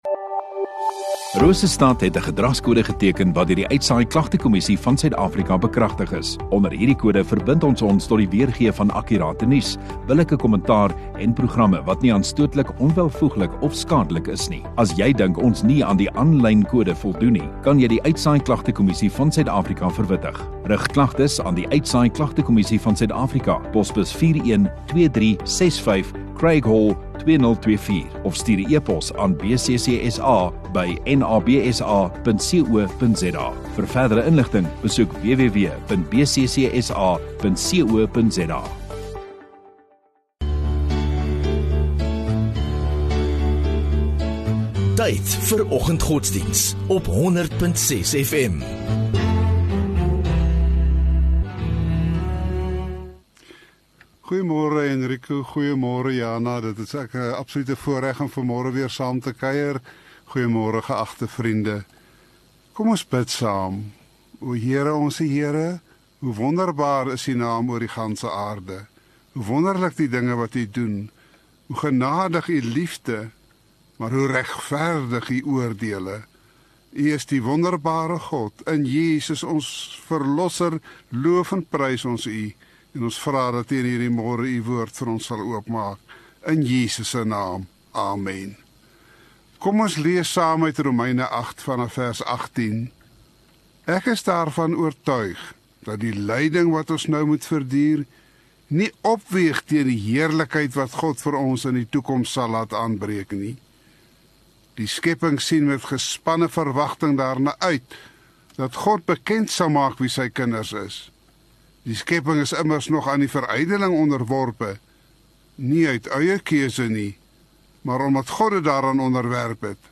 25 Mar Dinsdag Oggenddiens